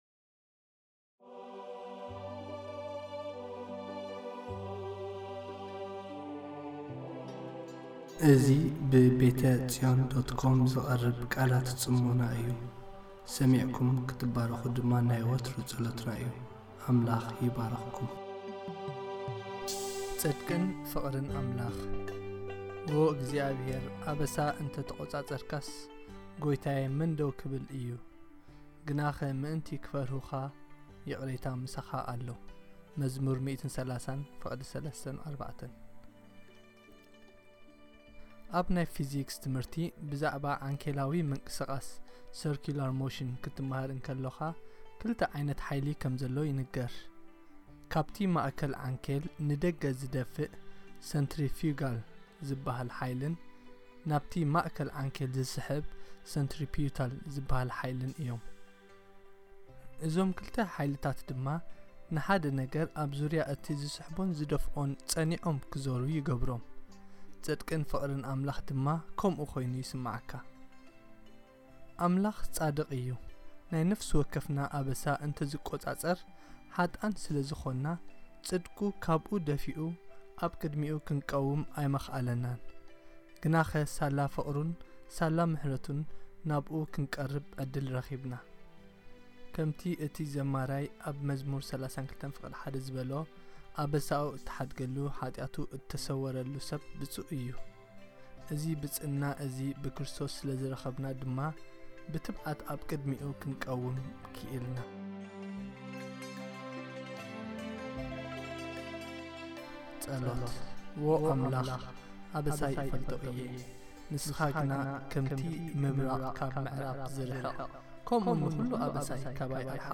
Devotional posted by